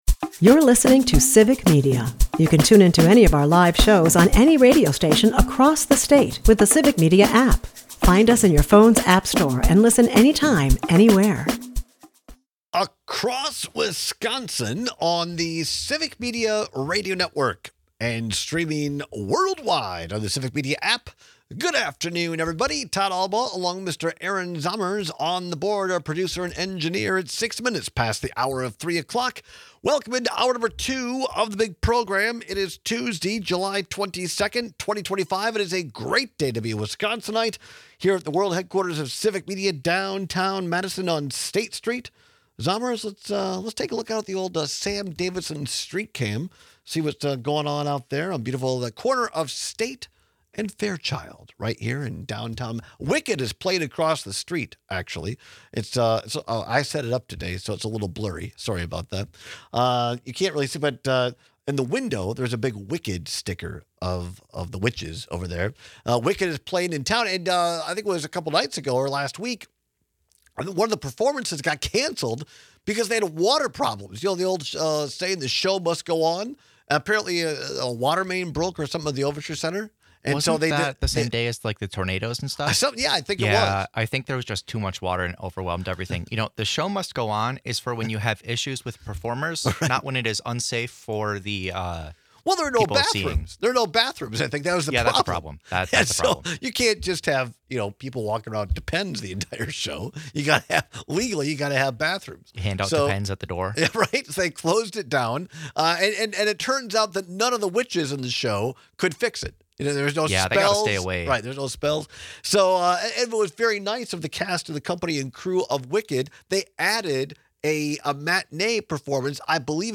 We take your calls and texts on which fluffy breakfast cake you prefer.